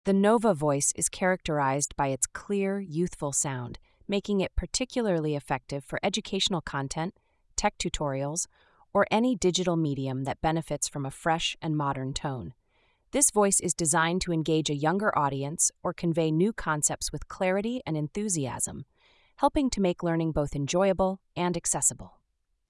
The “Nova” voice is characterized by its clear, youthful sound, making it particularly effective for educational content, tech tutorials, or any digital medium that benefits from a fresh and modern tone.